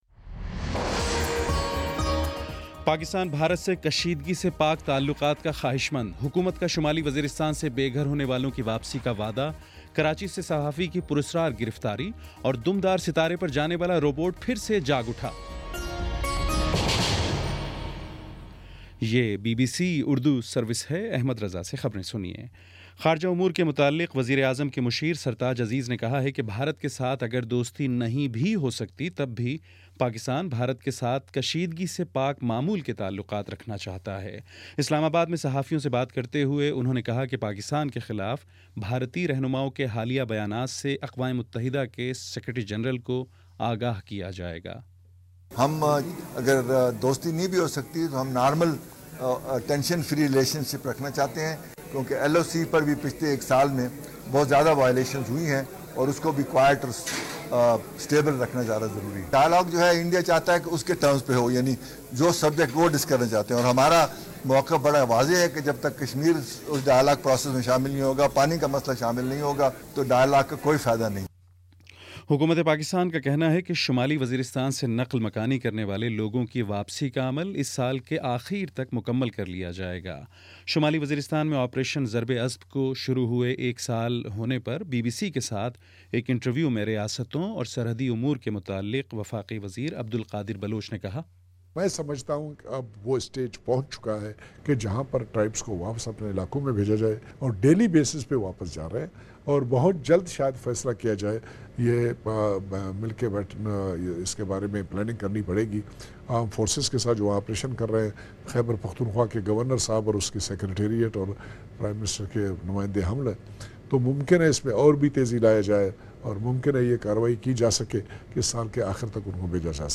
جون 15: شام پانچ بجے کا نیوز بُلیٹن